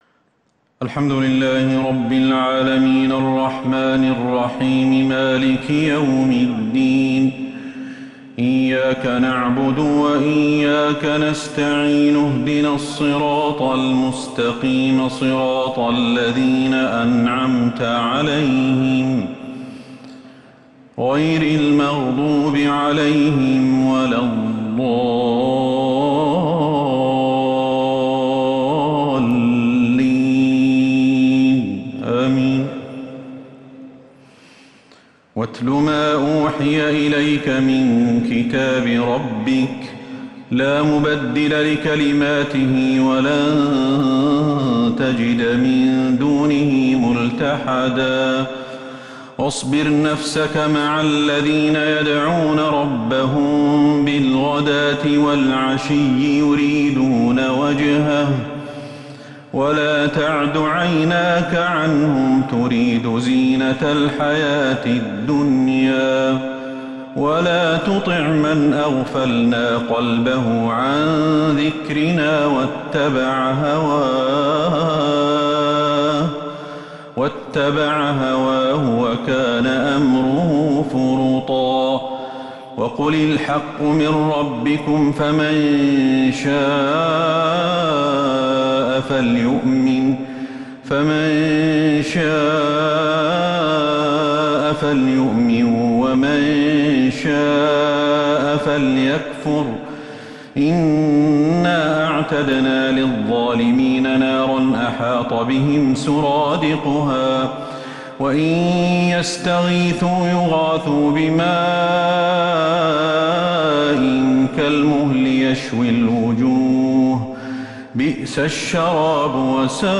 تراويح ليلة 20 رمضان 1442هـ من سورة الكهف Taraweeh 20 th night Ramadan 1442H > تراويح الحرم النبوي عام 1442 🕌 > التراويح - تلاوات الحرمين